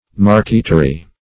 marqueterie \mar"que*te*rie\, marquetry \mar"quet*ry\, n. [F.